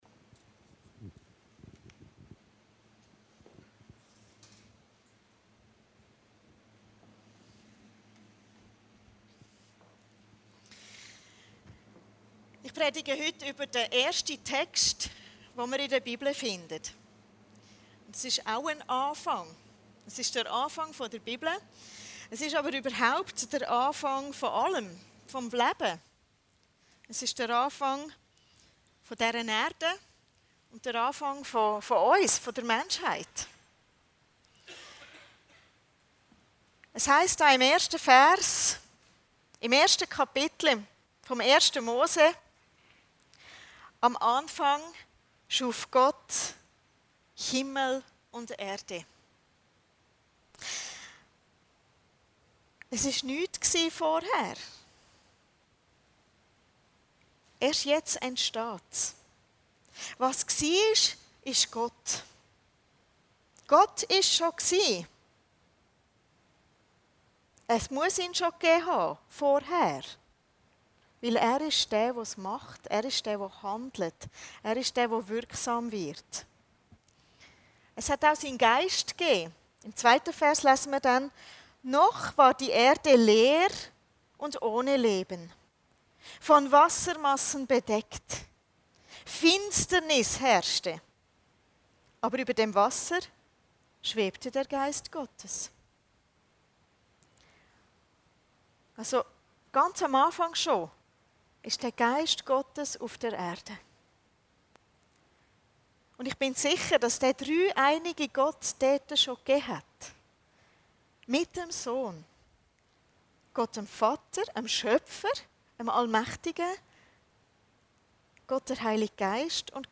Predigten Heilsarmee Aargau Süd – Die Schöpfung